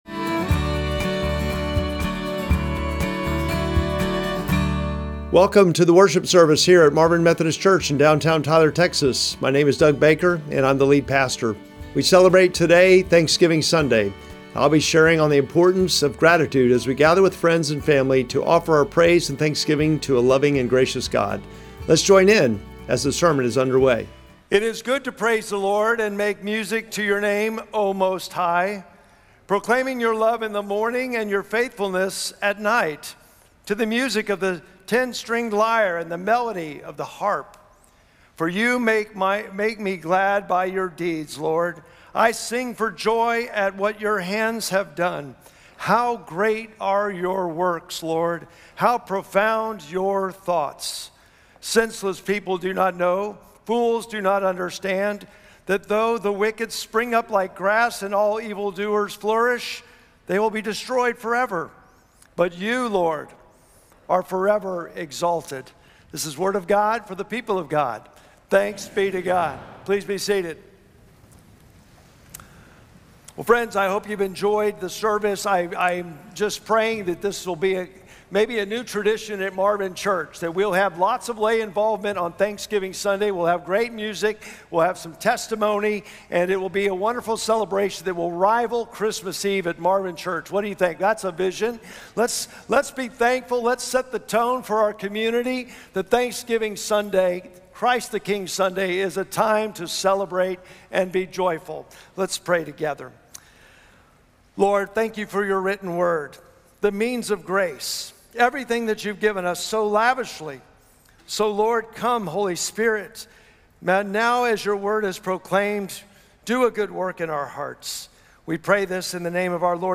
Sermon text: Psalm 92:1-8